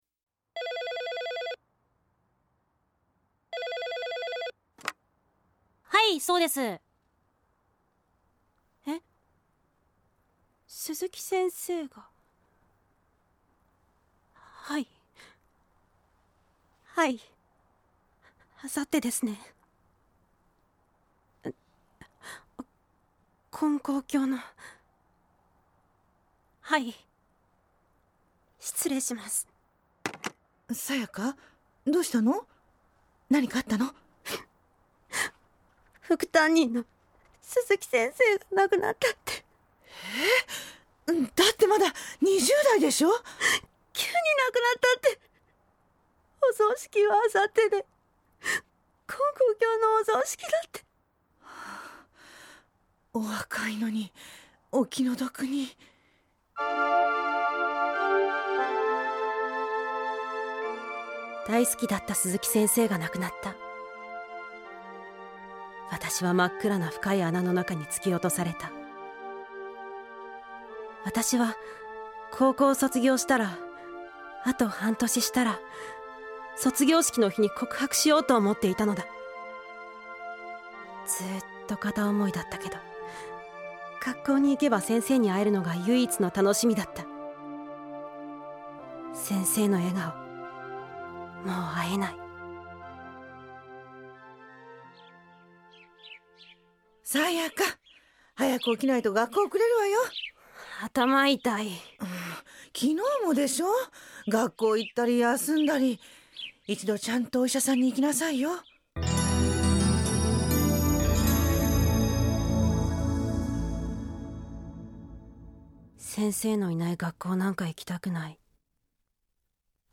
●ラジオドラマ「ようお参りです」
・先生（教会の先生・30代女性）
・男１（20代）